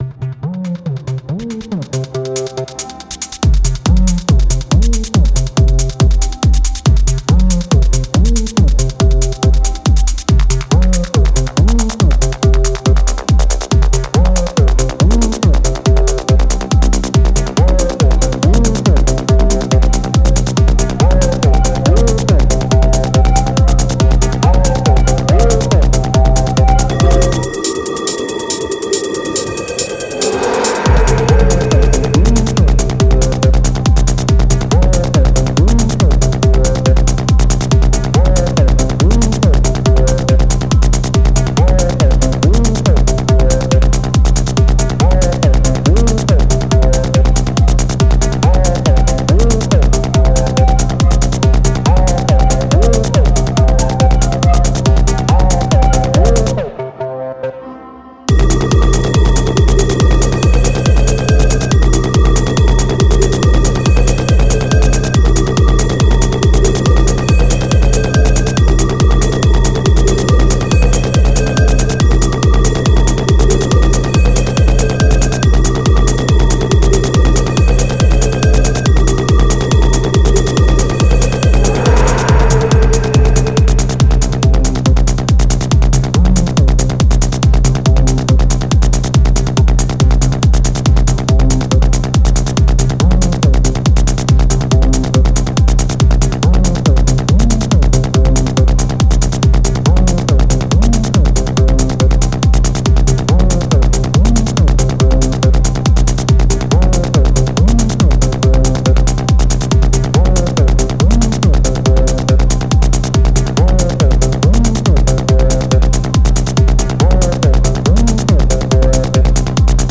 Here's a psytrance example of a distinct sound in and how it doesn't work so well if you continue to use it.